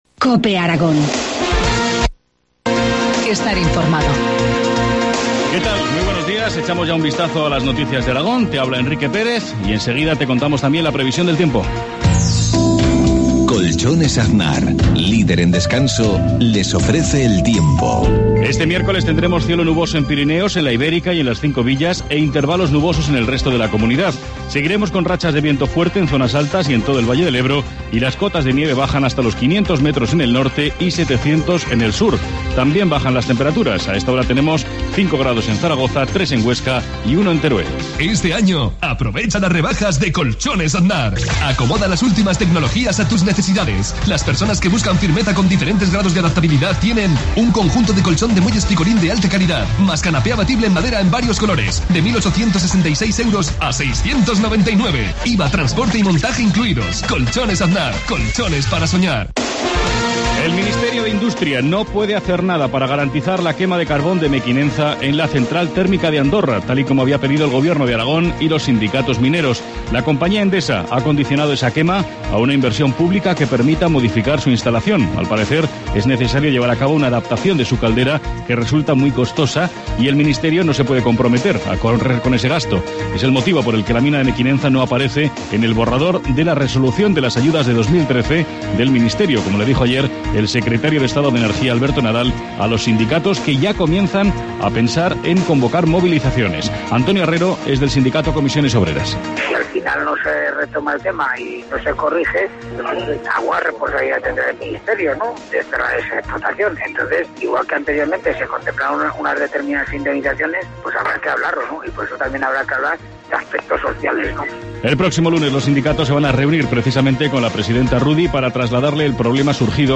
Informativo matinal, miércoles 6 de febrero, 7.53 horas